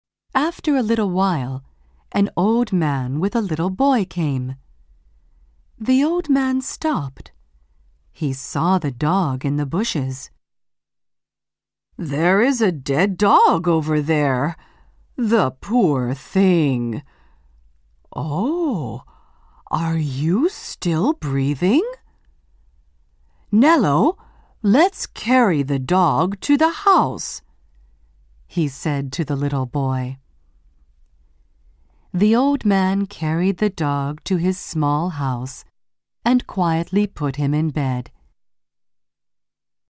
音声には効果音も取り入れていますので、学習者が興味を失わずに最後まで聴き続けることができます。